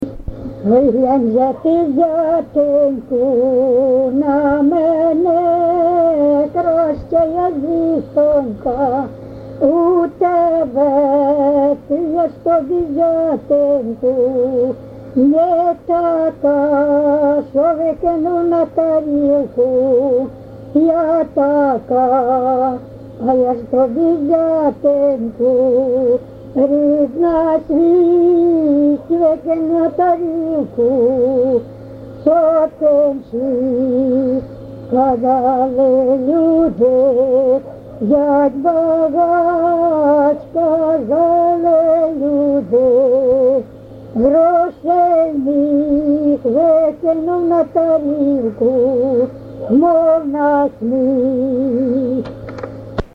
ЖанрВесільні
Місце записус. Коржі, Роменський район, Сумська обл., Україна, Слобожанщина